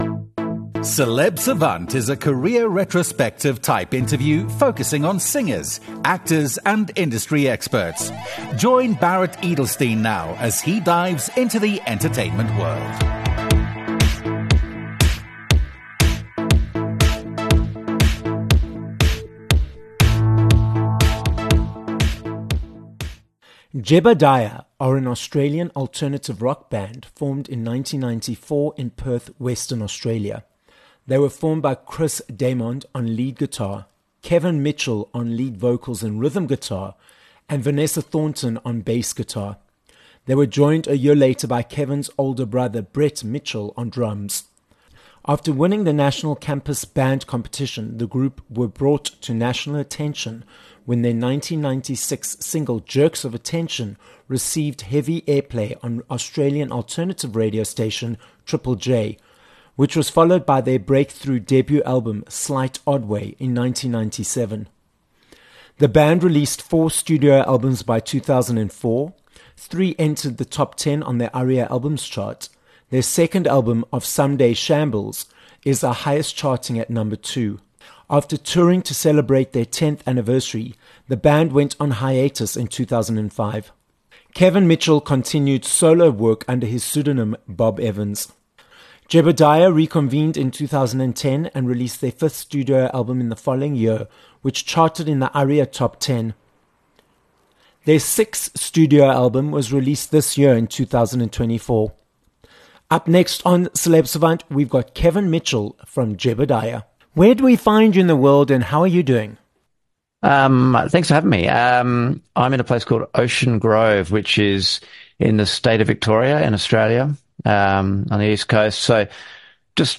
30 Apr Interview with Kevin Mitchell (Jebediah)